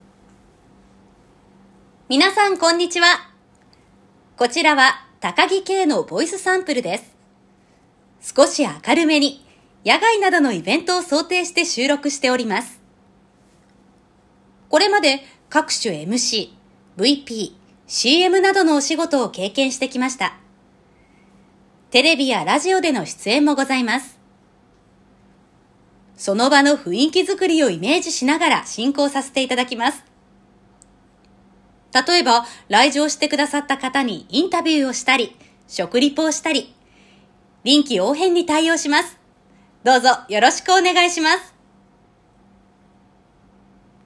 ナレーター｜MC｜リポーター